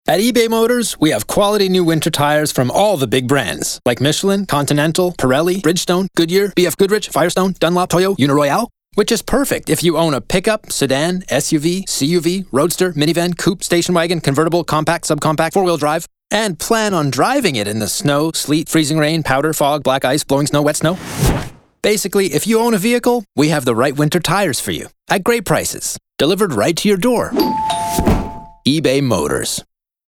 Publicité (Ebay) - ANG